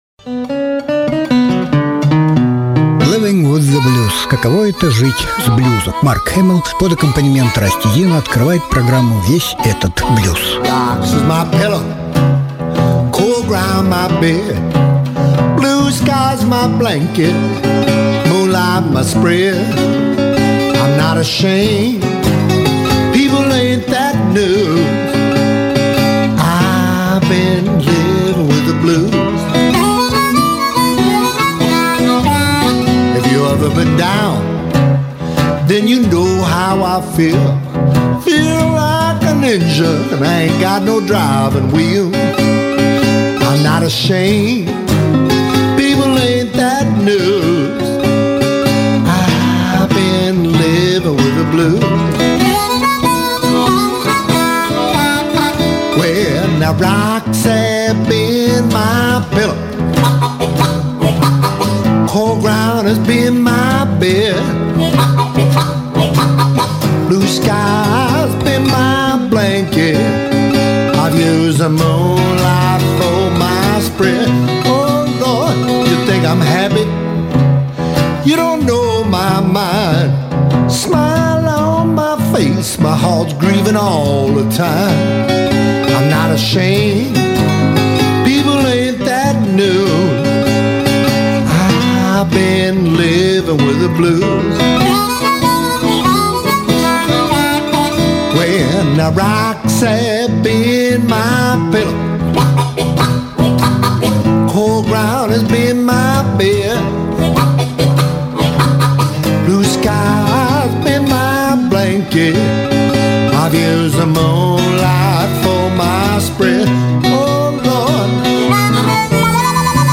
Mark Hummel - виртуоз губной гармоники.